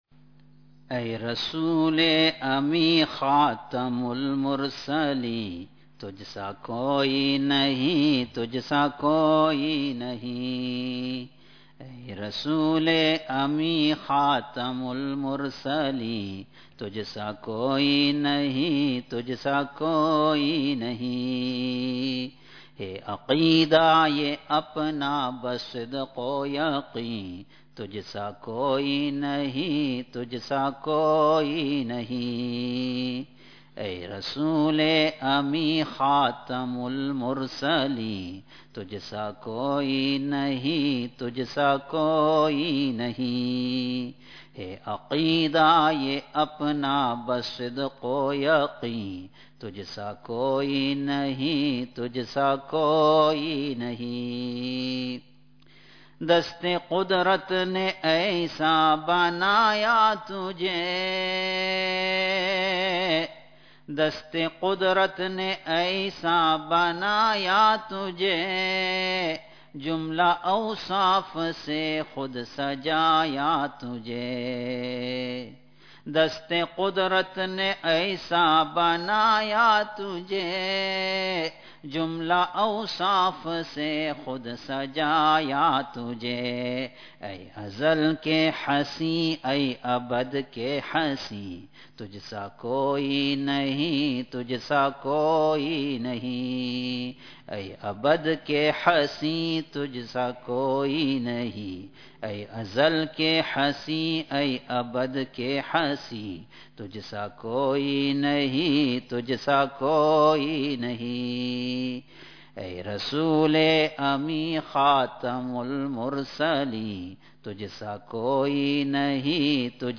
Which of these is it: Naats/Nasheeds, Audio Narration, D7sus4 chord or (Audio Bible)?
Naats/Nasheeds